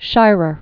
(shīrər), William Lawrence 1904-1993.